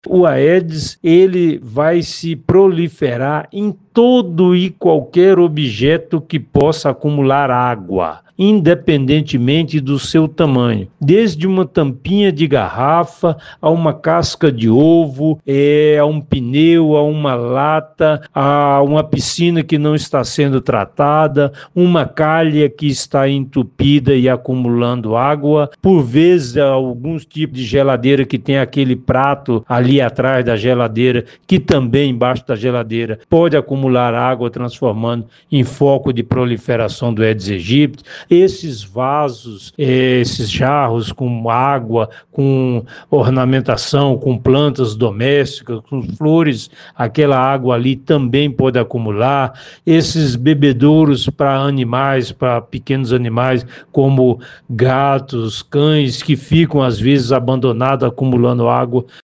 Em entrevista à FM Educativa MS o especialista deu mais esclarecimentos.